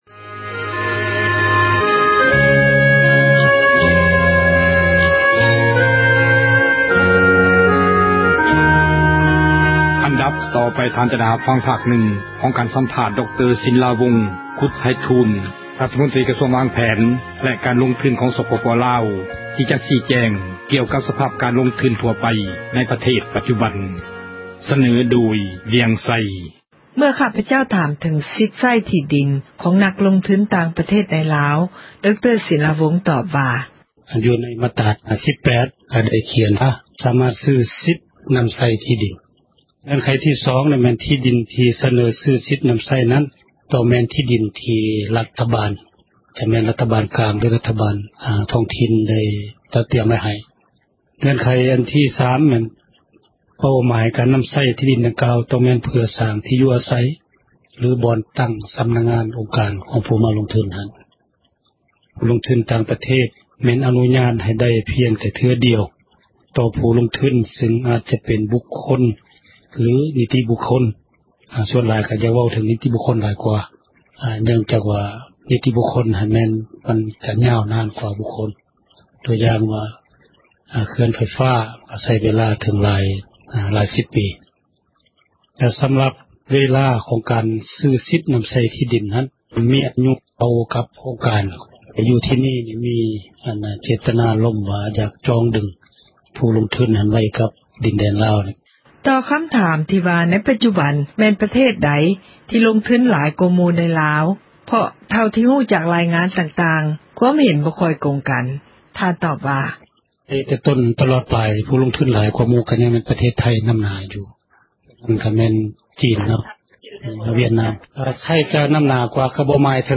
F-Sinlavong ດຣ.ສິນລາວົງ ຄຸດໄພທູນ ຣັຖມົນຕຣີ ກະຊວງວາງແຜນ ແລະການລົງທຶນ ຂອງສປປລາວ ໃຫ້ສັມພາດ ວິທຍຸເອເຊັຽເສຣີ ກ່ຽວກັບສະພາບ ການລົງທຶນ ທົ່ວໄປໃນປະເທດ